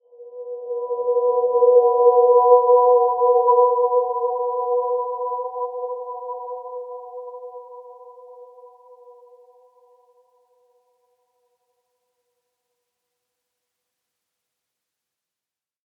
Dreamy-Fifths-B4-p.wav